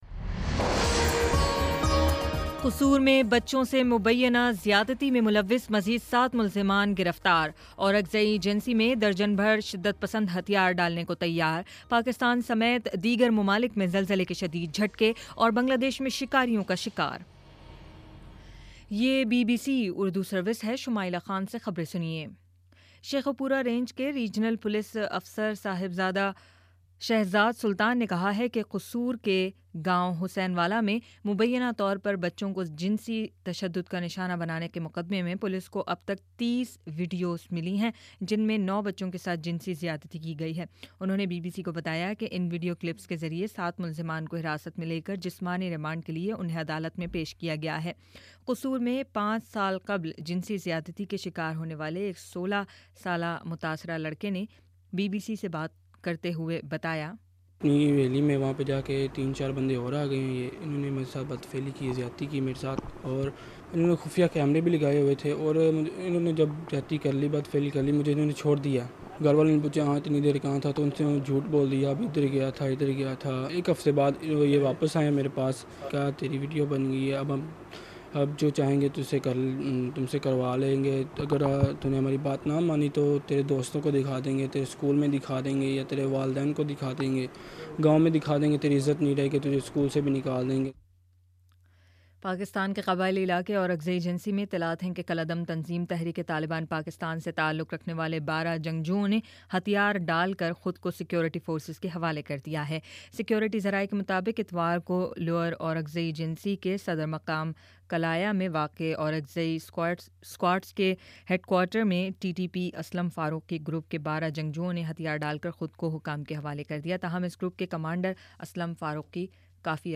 اگست 10: شام پانچ بجے کا نیوز بُلیٹن